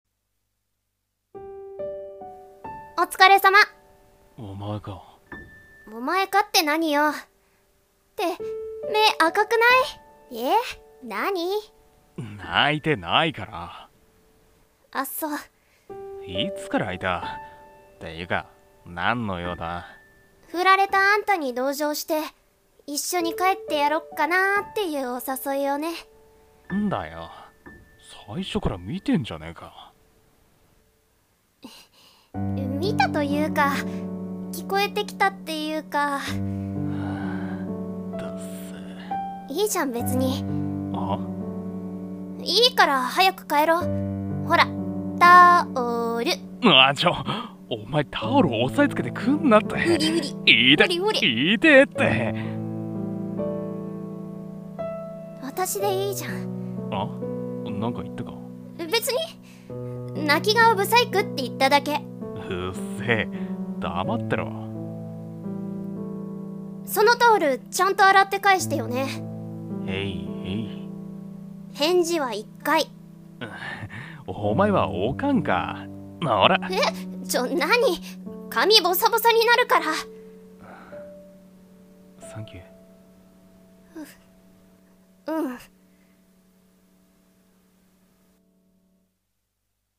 【二人声劇】